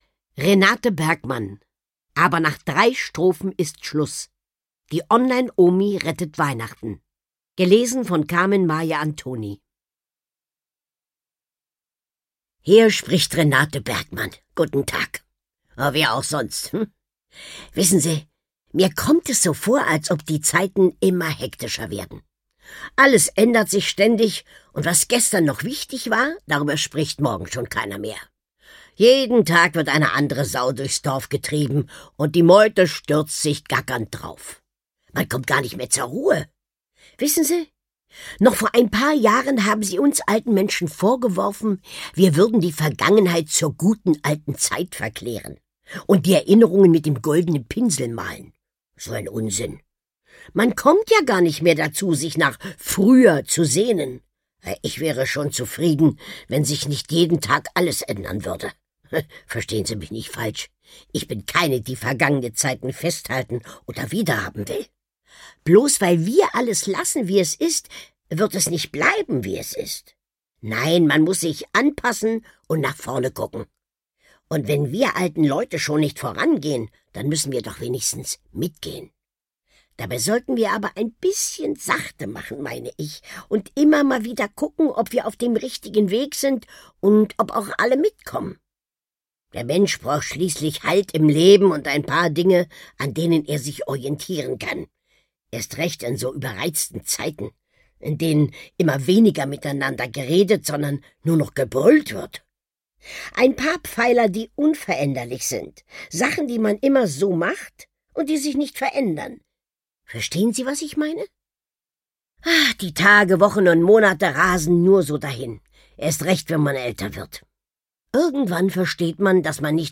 Carmen-Maja Antoni (Sprecher)
Lesung mit Carmen-Maja Antoni